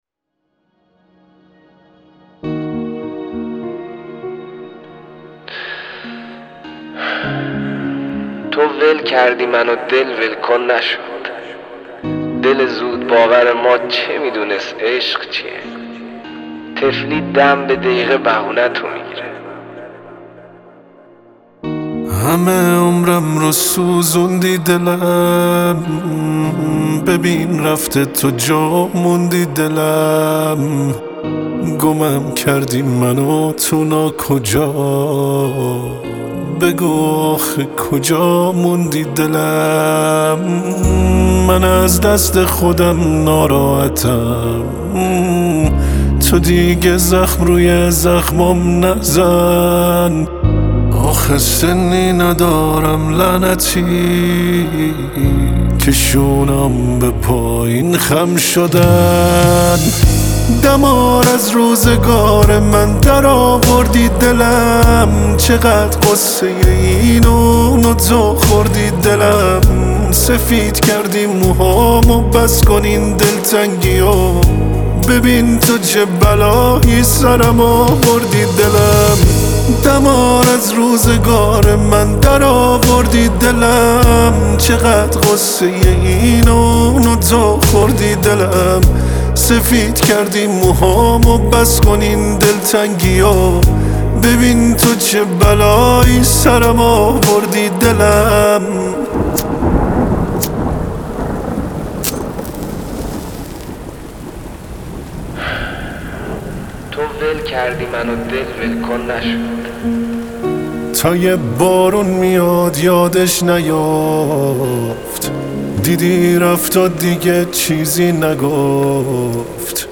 موزیک فول چس ناله ای
این موزیک جذاب و بغضی